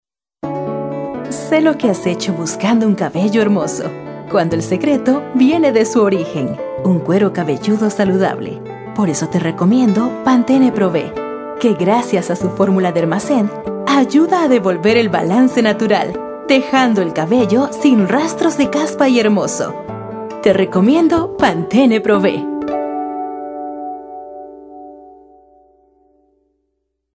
Locutora Comercial, locutora de Noticias, editora y productora de radio.
Sprechprobe: Sonstiges (Muttersprache):